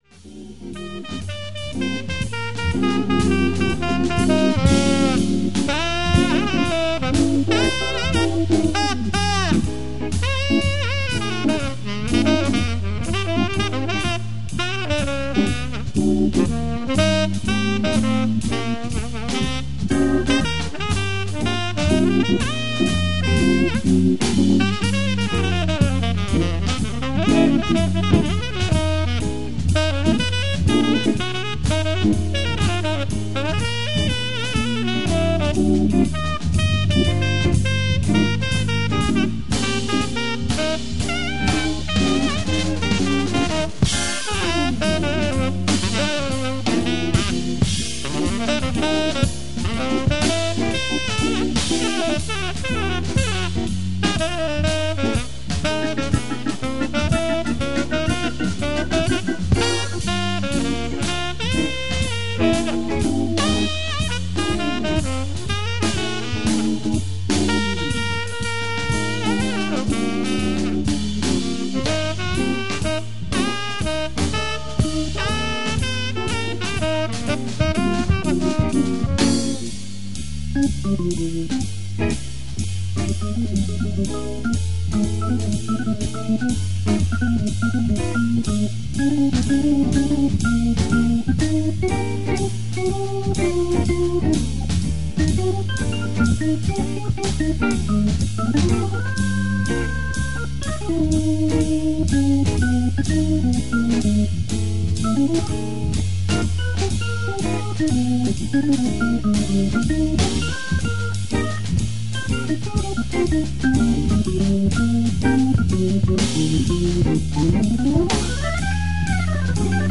orgue